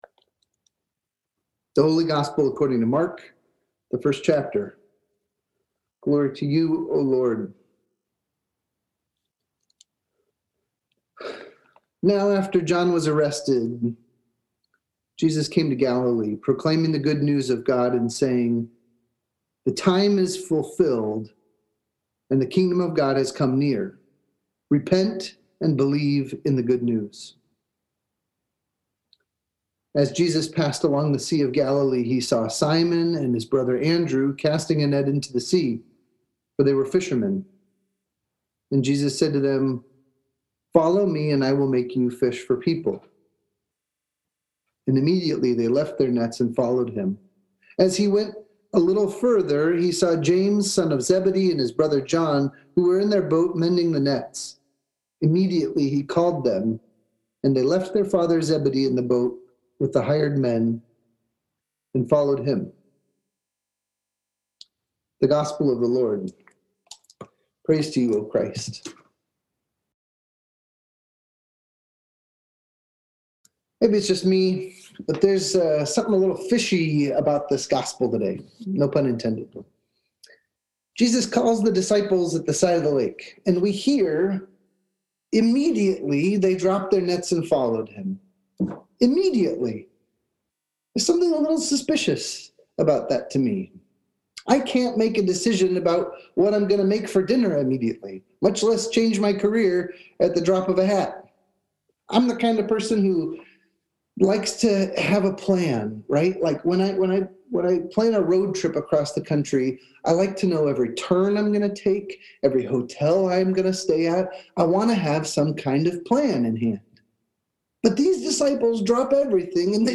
Sermons | Shepherd of the Valley Lutheran Church